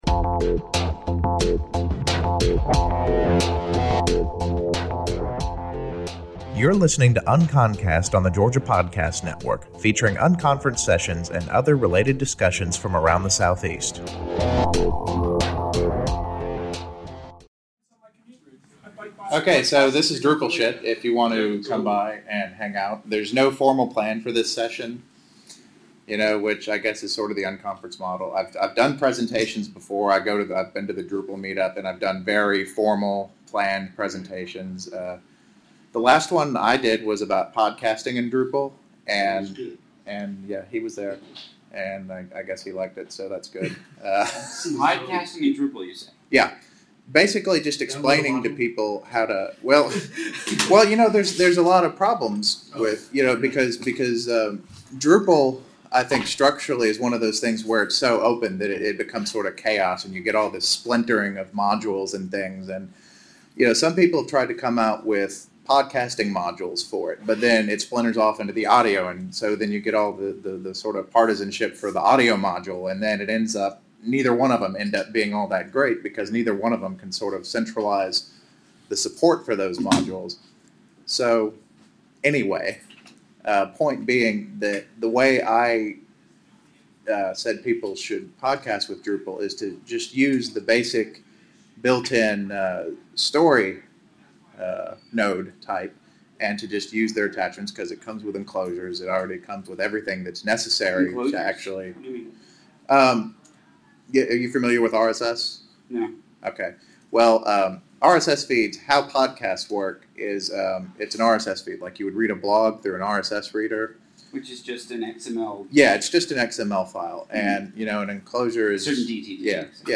Featuring unconference sessions and other related discussions from around the Southeast.